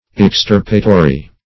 \Ex*tir"pa*to*ry\